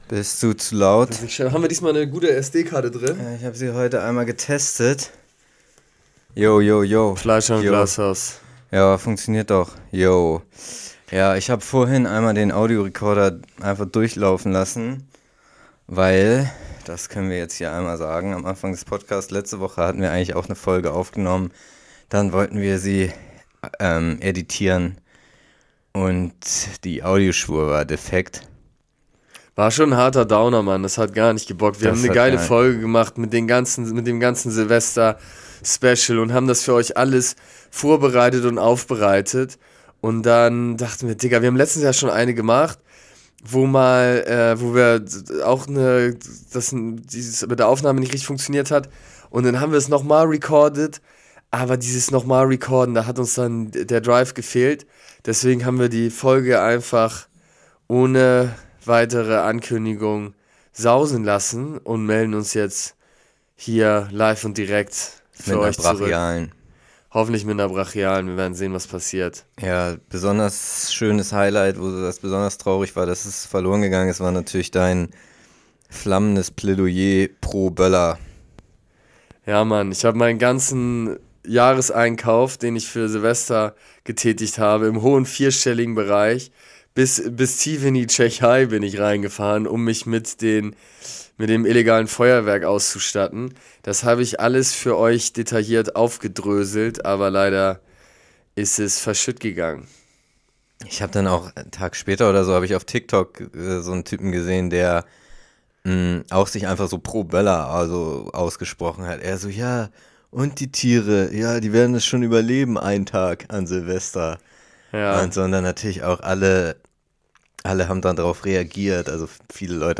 Heute mal wieder ne knackige Folge: Wir machen ein 4-Stunden langes Freestyle Battle mit unklarem Ausgang. Ihr seid die Jury.